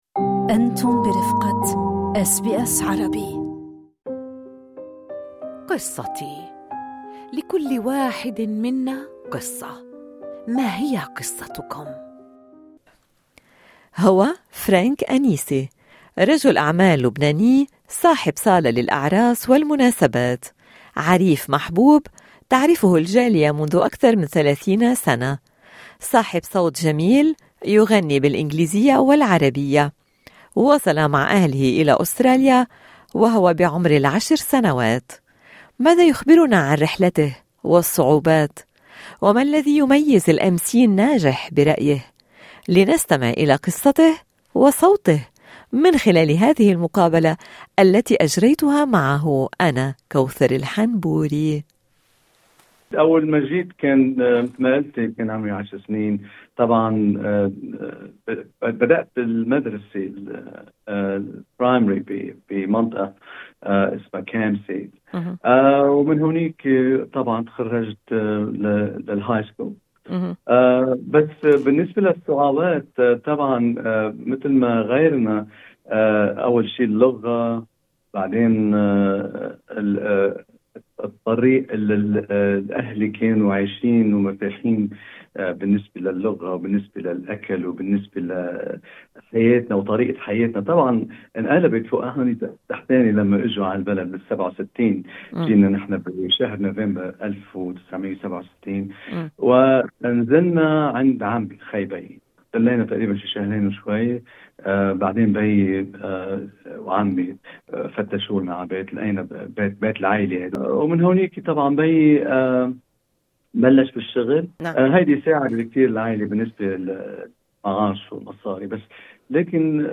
(يسمعنا مقطع خلال المقابلة)